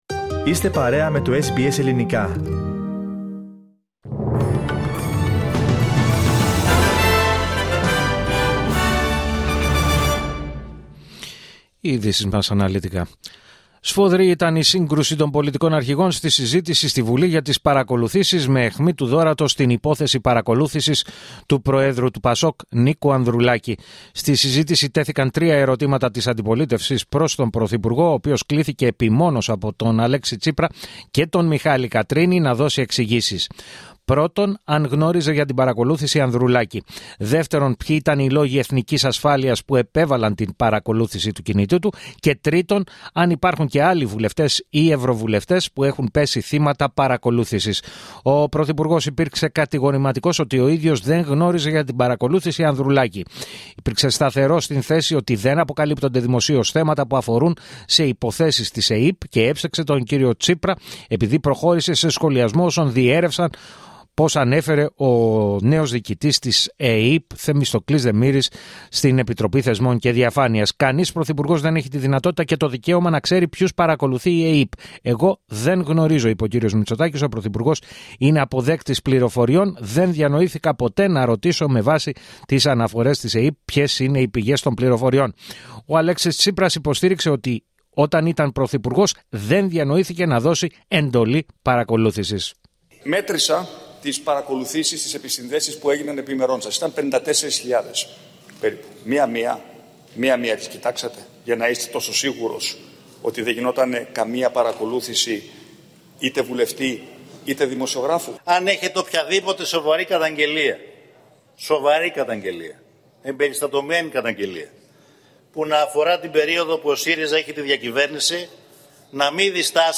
Το αναλυτικό δελτίο ειδήσεων από το Ελληνικό Πρόγραμμα της ραδιοφωνίας SBS, στις 4 μμ.